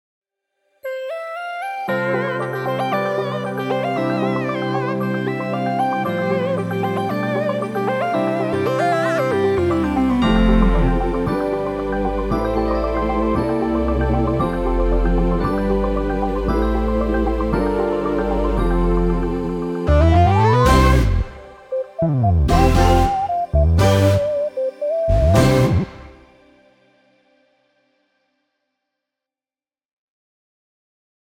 A versatile collection of 30 unique Omnisphere presets crafted for Gospel, Live Arrangements, Fusion, Rnb, Pop and Afro-beats! This pack offers a rich palette of sounds including synth leads, synth brasses, arps, plucks, pads, bells, keyboards, a tubular bells and orchestral hit, bells, choirs, synth basses and a lush string ensemble.
2 Arps 8 Synths Leads 2 Synth Brasses 2 Plucks 3 Bells including a Tubular Bell 1 Orchestral Hit 3 Keyboard Patches 5 Pads/Choirs 1 String Ensemble 1 Sine Pad 1 Moog Bass and 1 Sub Bass